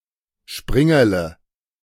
Springerle (German: [ˈʃpʁɪŋɐlə]